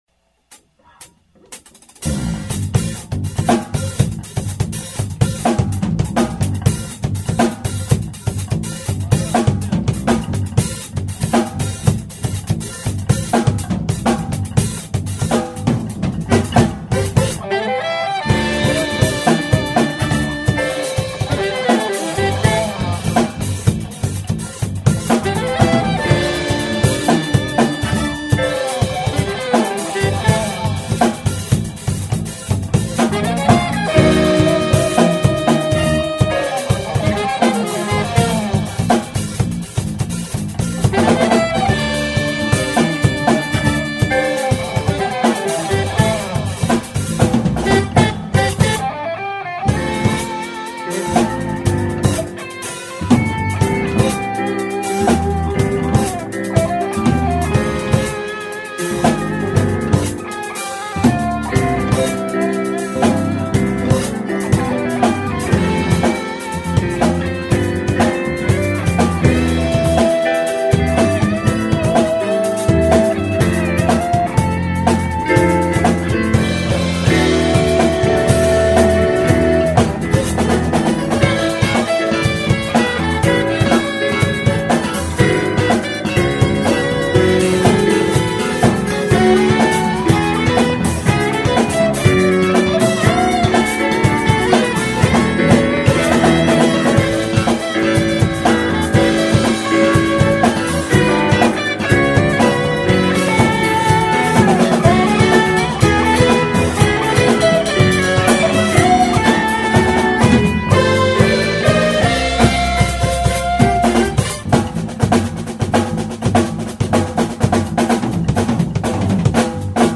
합주 녹음